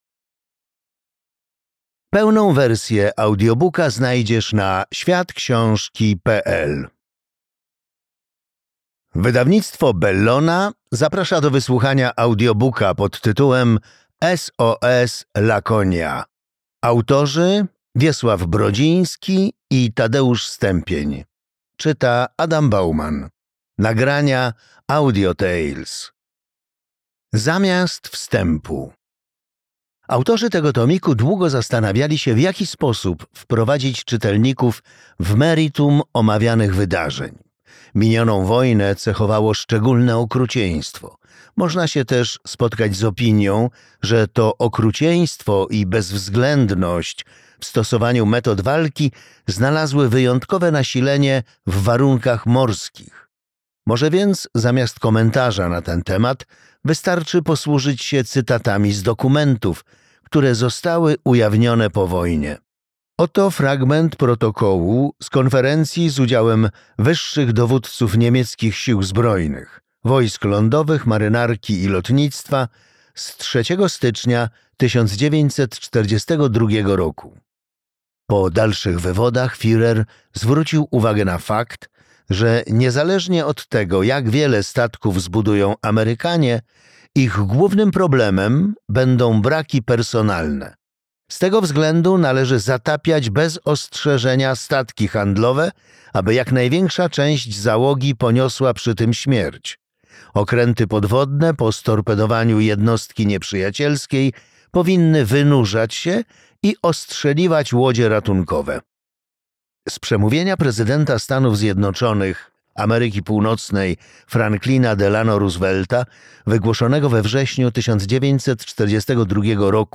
SOS "Laconia" - Wiesław Brodziński, Tadeusz Stępień - audiobook